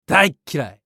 男性
熱血系ボイス～恋愛系ボイス～